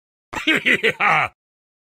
Clash Royale HI HI HI sound effects free download
Clash Royale HI HI HI HAA !